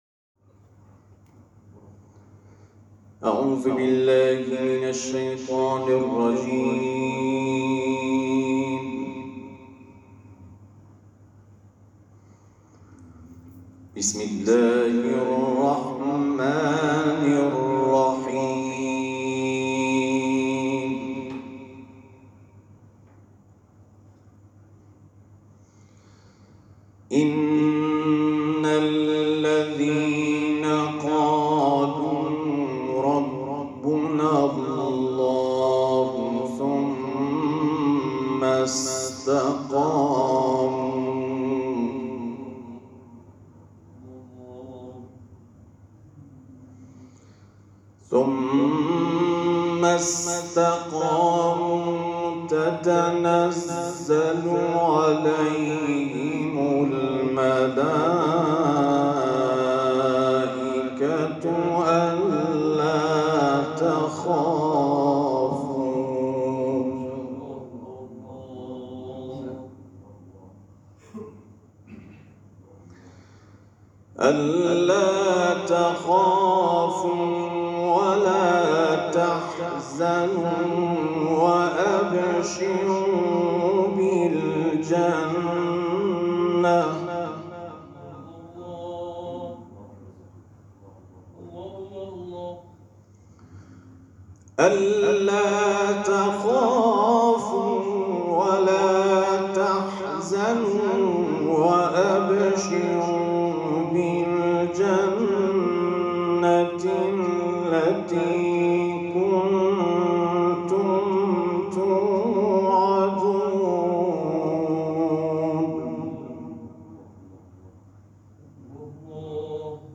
تلاوت قرآن ، سوره فصلت